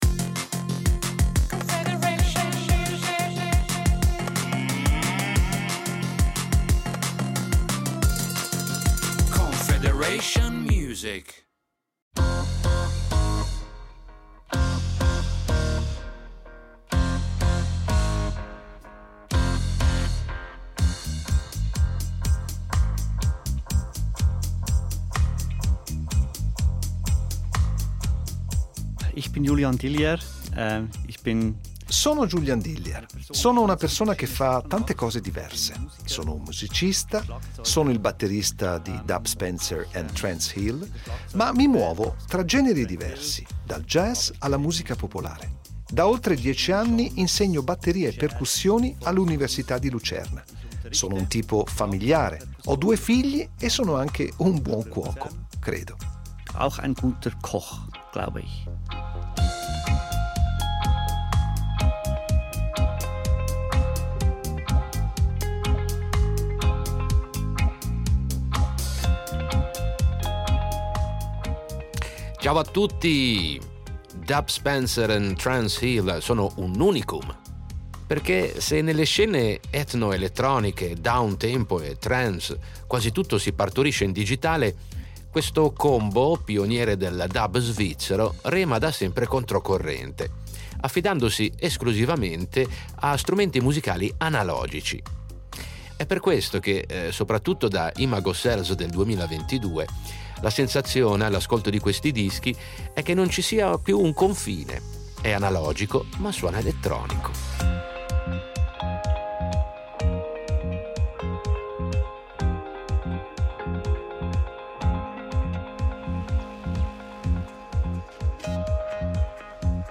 È un perfezionamento di quel concetto, un ibrido perfetto tra dub e trance: analogico, ballabile e tecnologicamente all’avanguardia quando viene portato in scena.
Ora tutto nasce da jam sessioni d’improvvisazione in cui un groove accattivante viene ripetuto ad oltranza innescando l’effetto ipnotico e l’elemento trance tipici della loro musica attuale.